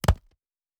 pgs/Assets/Audio/Fantasy Interface Sounds/UI Tight 01.wav at master
UI Tight 01.wav